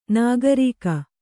♪ nāgarīka